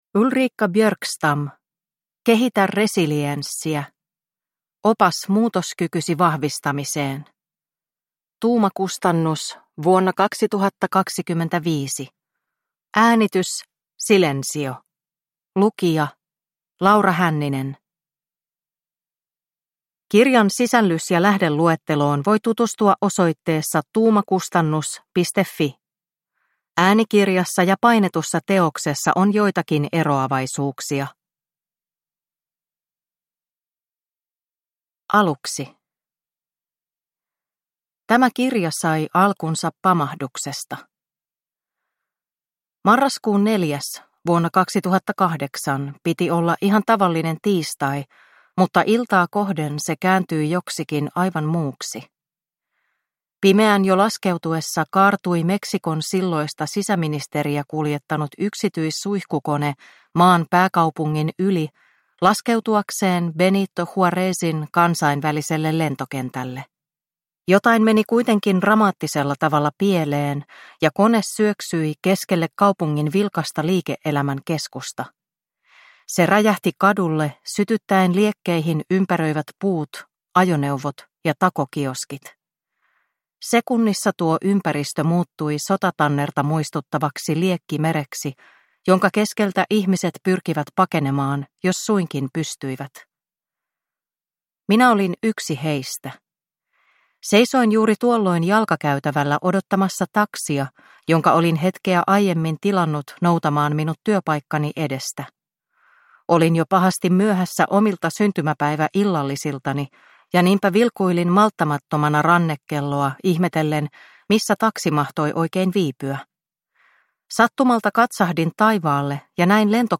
Kehitä resilienssiä (ljudbok) av Ulrika Björkstam